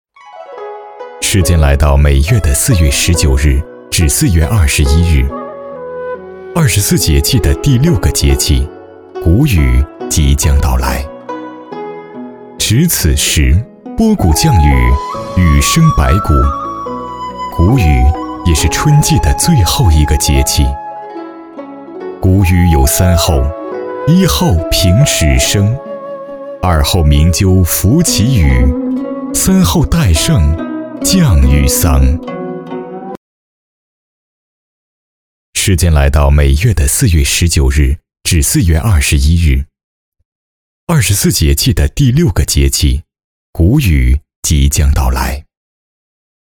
娓娓道来 文化历史
高性价比男中音，音色偏稳重，擅长工程解说、政府汇报、企业宣传片等不同题材。